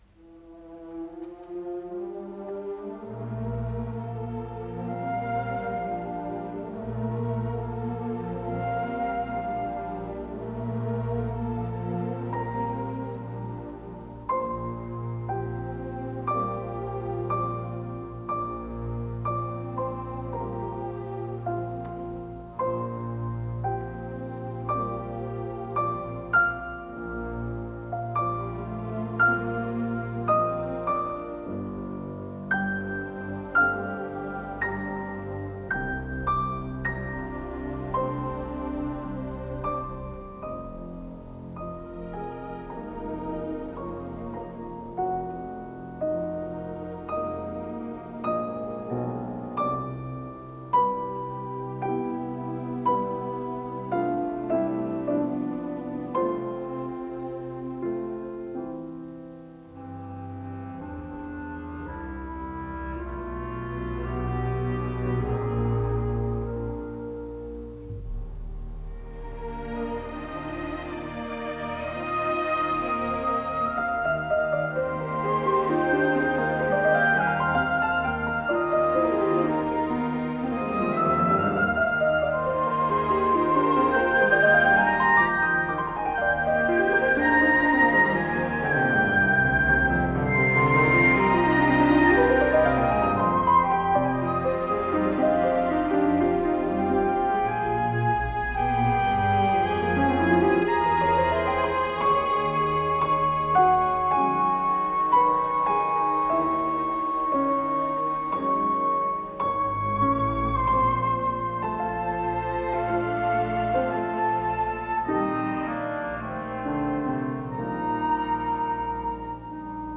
Исполнитель: Лондонский симфонический оркестр
Название передачи Концерт №5 для фортепиано с оркестром Подзаголовок Соч. 55. Соль мажор.